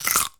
comedy_bite_chew_05.wav